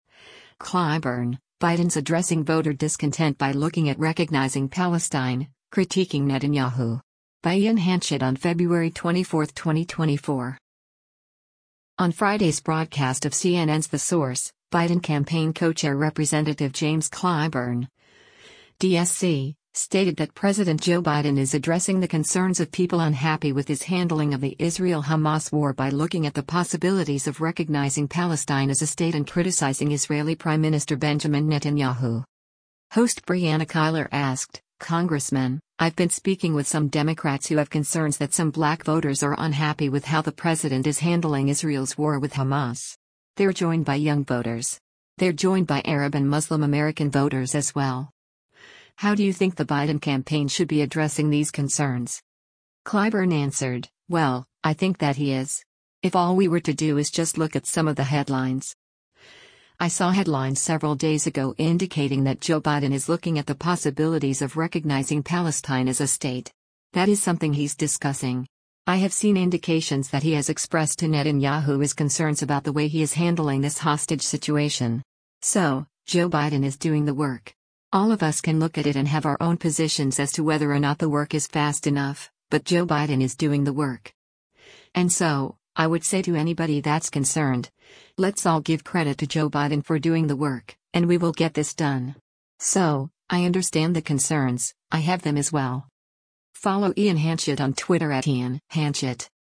On Friday’s broadcast of CNN’s “The Source,” Biden Campaign Co-Chair Rep. James Clyburn (D-SC) stated that President Joe Biden is addressing the concerns of people unhappy with his handling of the Israel-Hamas war by “looking at the possibilities of recognizing Palestine as a state” and criticizing Israeli Prime Minister Benjamin Netanyahu.